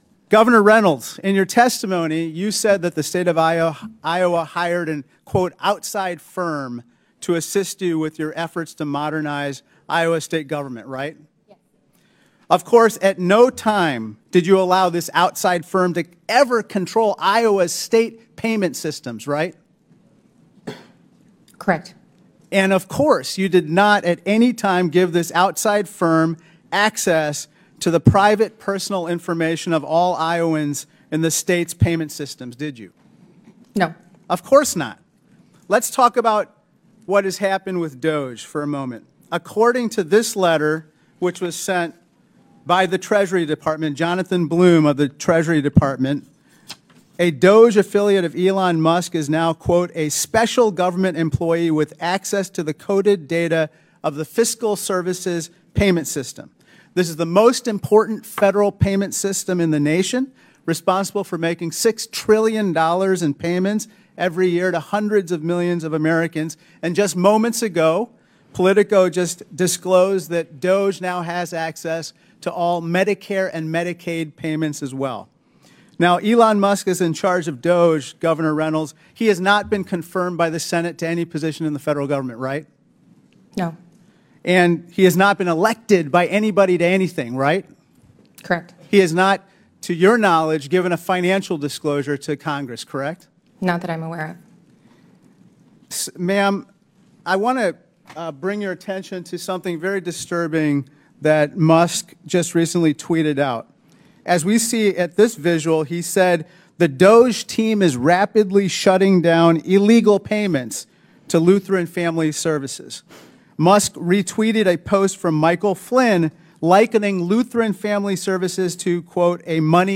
Governor Kim Reynolds, IA, being questioned at House hearing.mp3